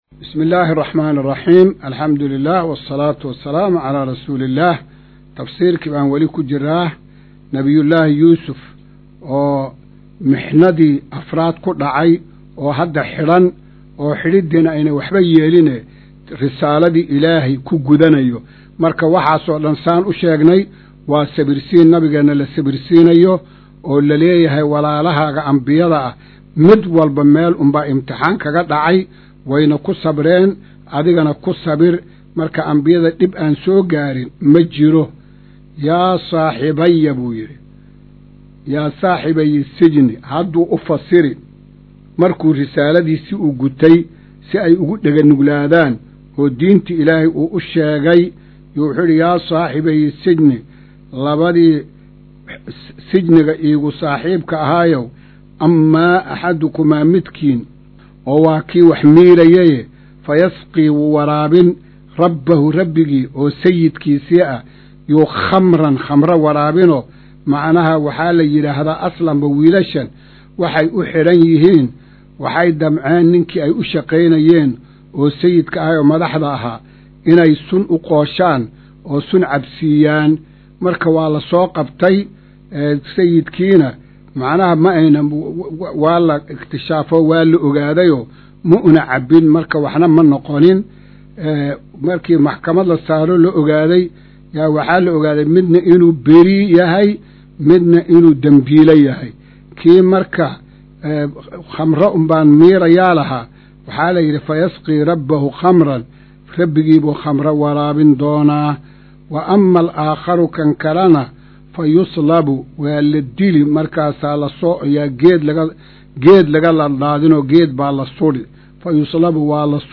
Hawlaha baahineed ee idaacadda himilo, waxaa gadda hore kaga jira barnaamijyo diini ah kuwaas oo isugu jira Fiqi, Xadiith, Siiro, Tafsiirka Qur’aanka Kariimka, iyo mawaadiic kale oo aan danta dadweynaha u aragno.